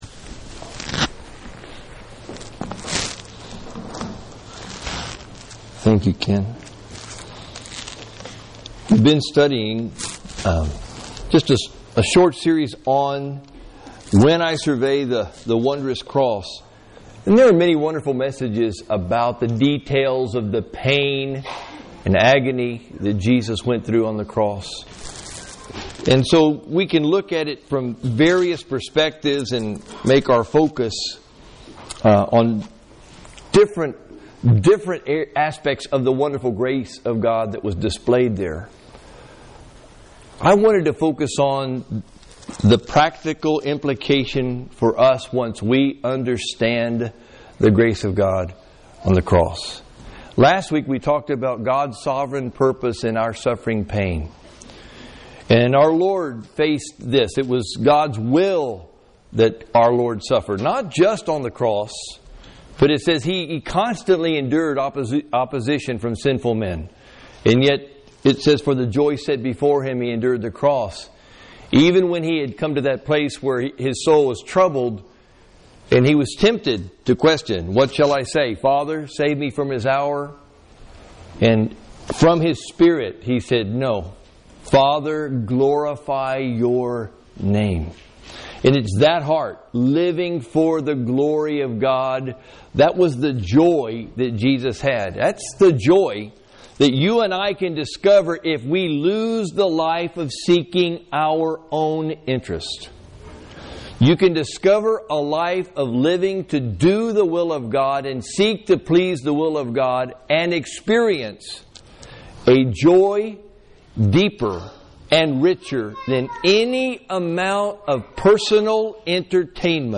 Sunday Message - University Park Baptist